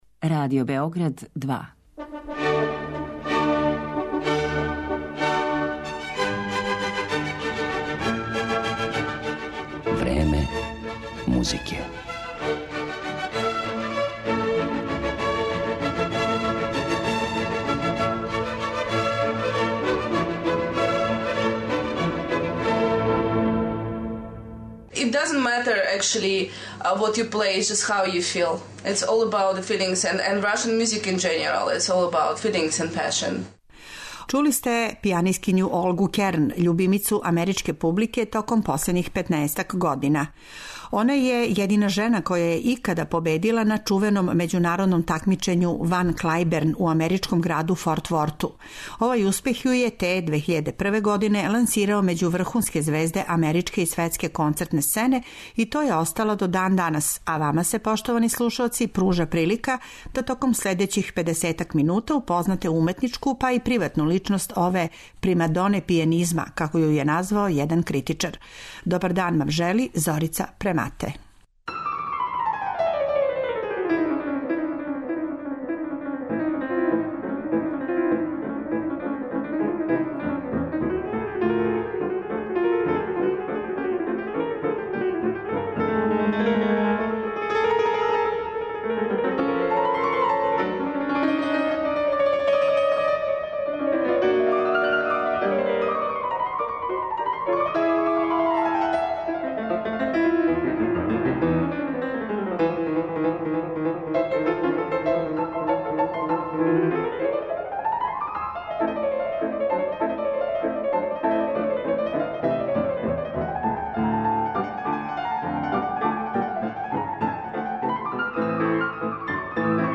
У емисији 'Време музике' свираће вам једна од популарних америчких пијанистичких звезда, Рускиња Олга Керн, која је, након победе на великом такмичењу 'Вај Клајберн' остала у Сједињеним Државама и изградила блиставу каријеру управо на немачком и руском романтичарском репертоару.
У емисији ћете, између осталих, слушати и њене интерпретације дела Рахмањинова,Тањејева, Љадова и Балакирјева.